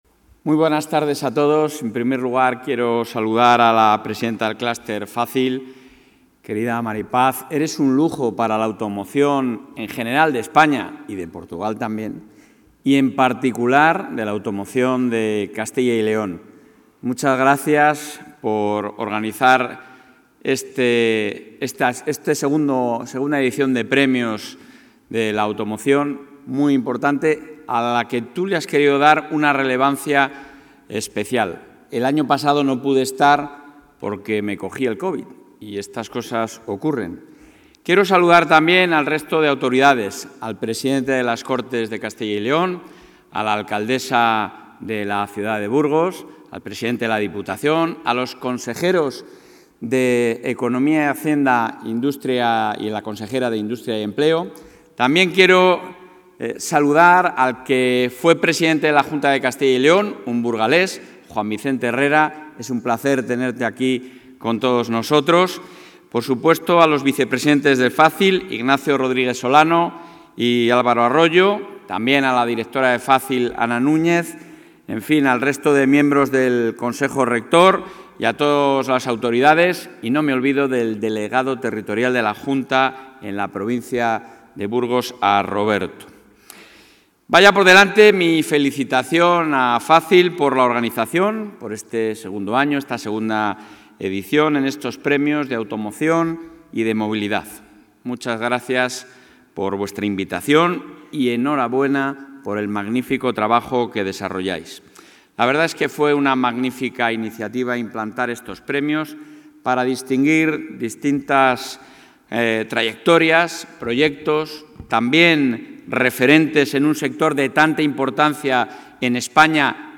Durante su intervención en la gala de los II Premios de la Automoción y la Movilidad de Castilla y León celebrada en Burgos, el...
Intervención del presidente de la Junta.